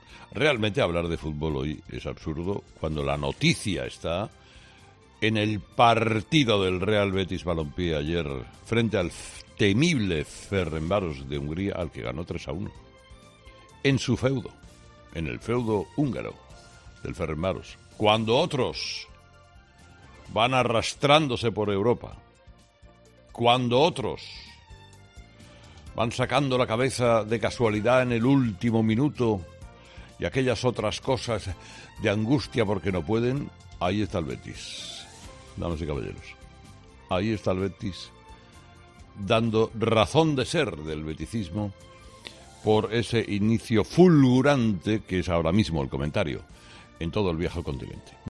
El director y presentador de 'Herrera en COPE' analizaba la victoria de su equipo frente al Ferencvaros